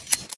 Minecraft Version Minecraft Version snapshot Latest Release | Latest Snapshot snapshot / assets / minecraft / sounds / mob / sheep / shear.ogg Compare With Compare With Latest Release | Latest Snapshot
shear.ogg